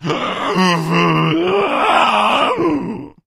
fracture_attack_7.ogg